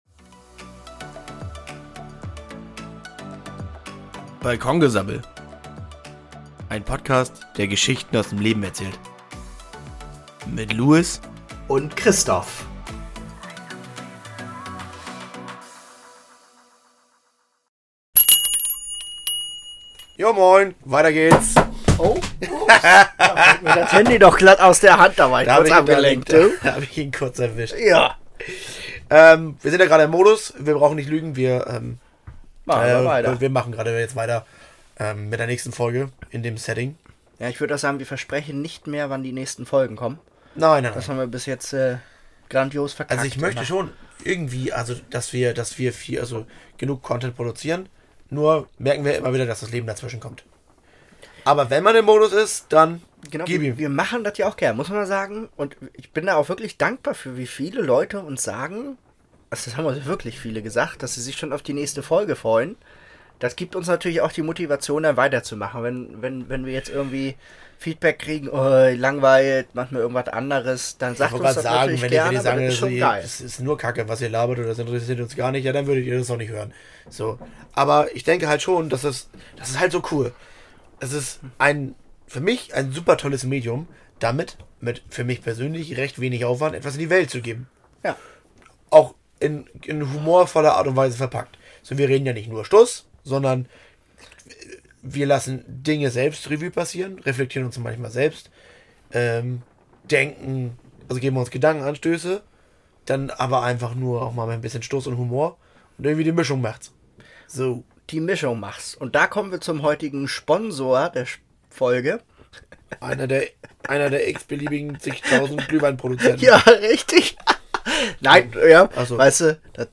Zugegeben, in dieser Folge sabbel ich verdammt viel und schnell, musste wohl mal raus^^